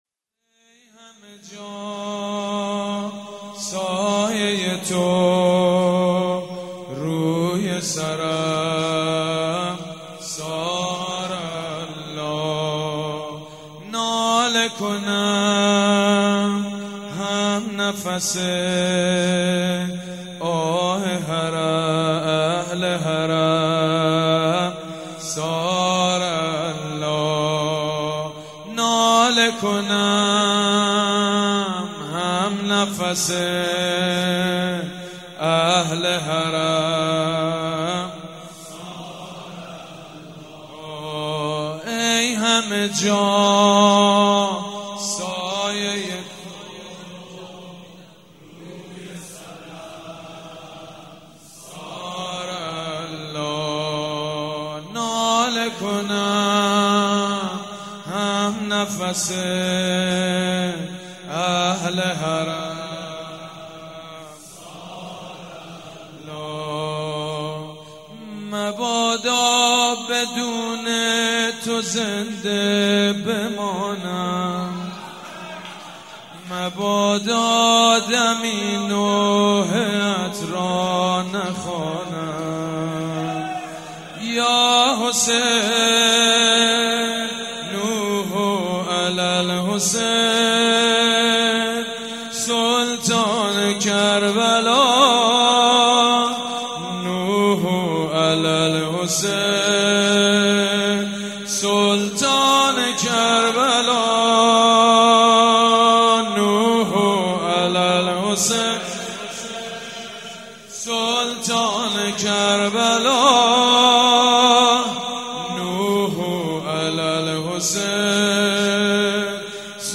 مراسم شب اول محرم 94